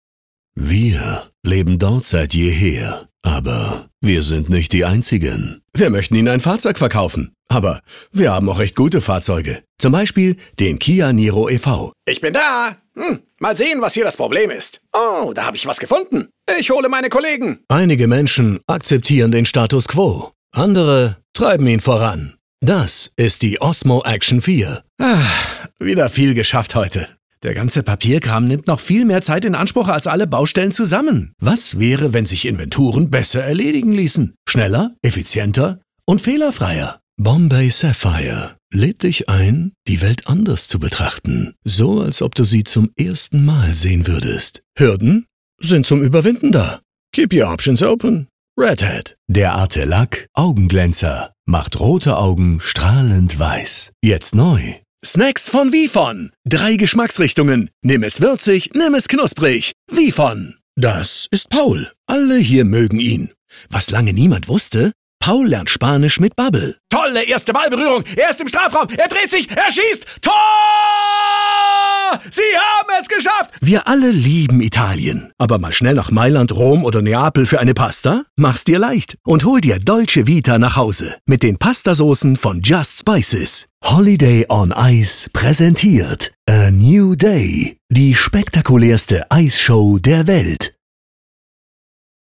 Male
Assured, Character, Confident, Cool, Corporate, Deep, Engaging, Friendly, Gravitas, Natural, Reassuring, Sarcastic, Soft, Wacky, Warm, Witty, Versatile, Young
German (native), Bavarian (native), English with German accent (native), German with English accent, English with South American Accent, German with several European foreign accents.
Microphone: Neumann U87, Neumann M149, Brauner Phantom Anniversary Edition etc.